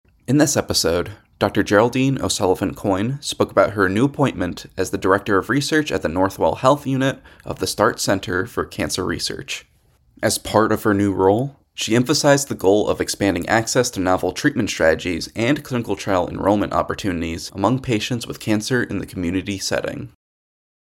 In a conversation with CancerNetwork®